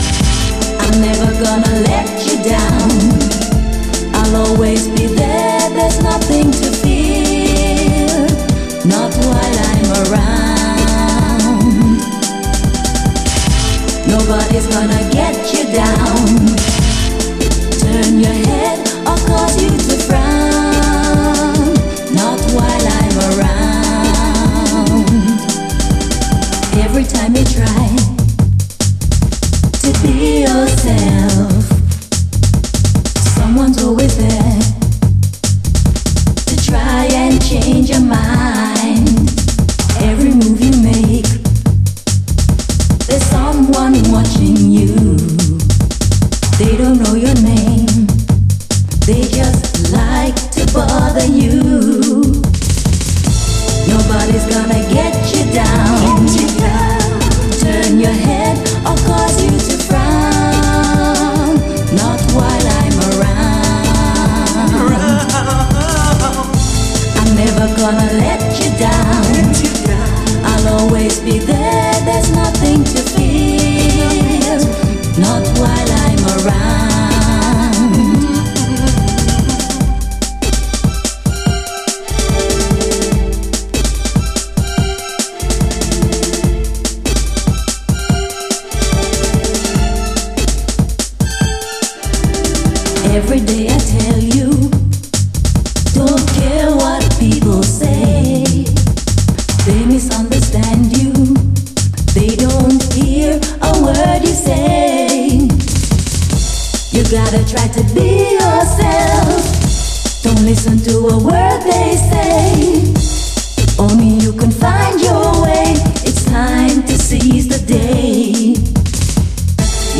REGGAE, DANCE
95年マイナー・メロウ・ジャングル！